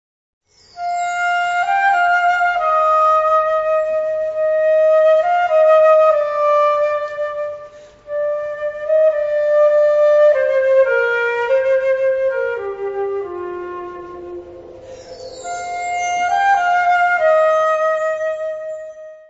Kategorie Blasorchester/HaFaBra
Unterkategorie Suite
Besetzung Ha (Blasorchester)